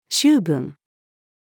醜聞-female.mp3